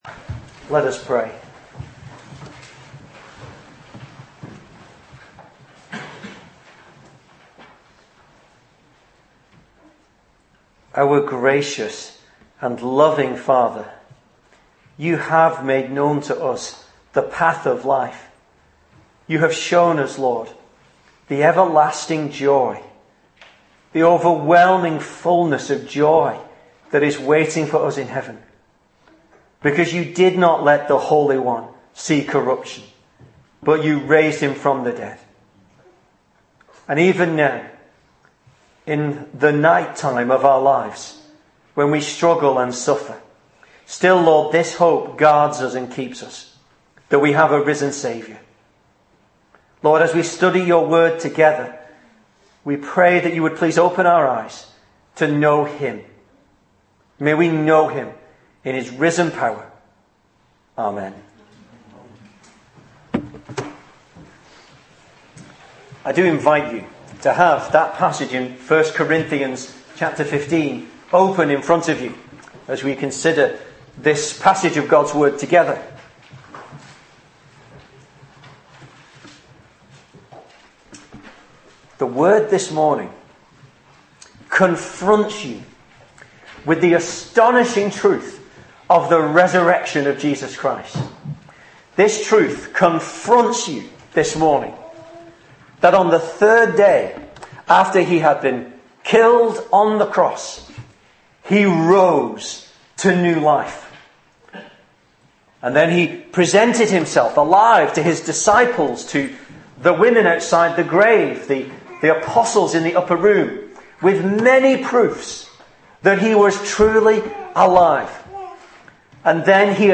Easter Sermons